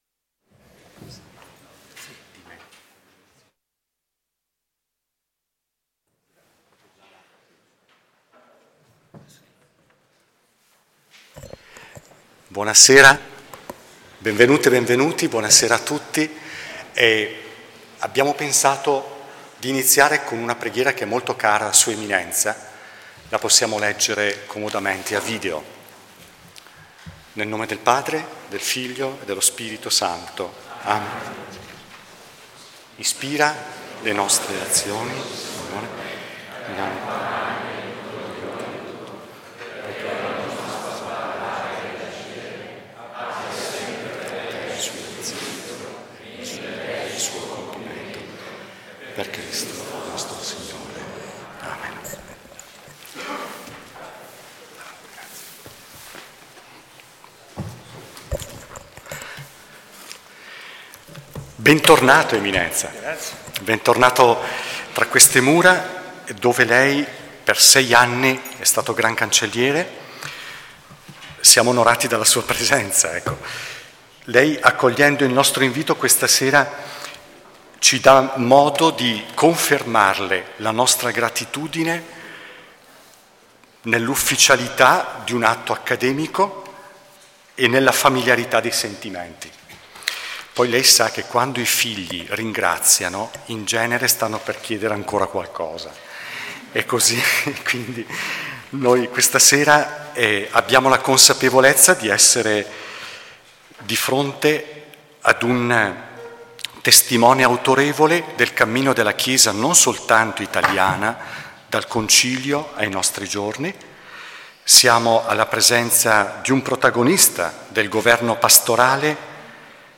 Giovedì 4 aprile 2019 presso la Sede centrale della Facoltà Teologica dell’Italia Settentrionale, in Via Cavalieri del Santo Sepolcro 3, a Milano, si è tenuta una Conversazione teologica con il Cardinal Scola a partire dalla sua Autobiografia, Ho scommesso sulla libertà, Solferino editore.